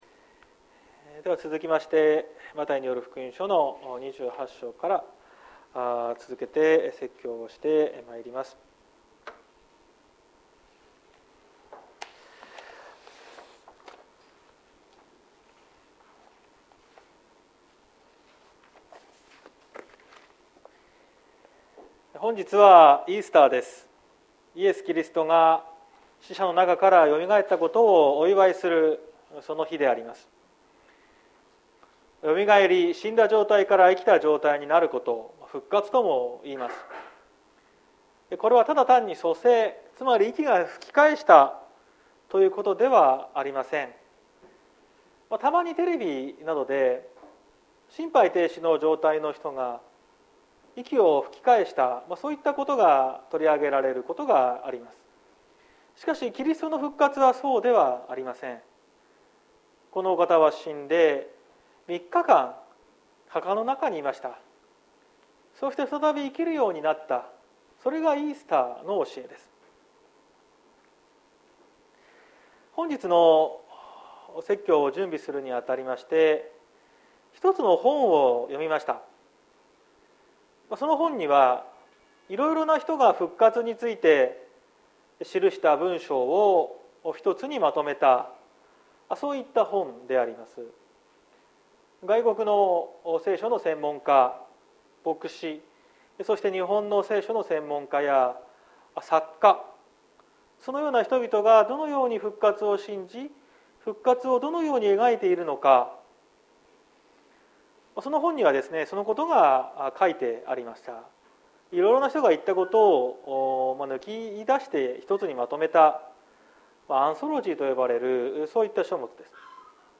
2022年04月17日朝の礼拝「死者の中からの復活」綱島教会
説教アーカイブ。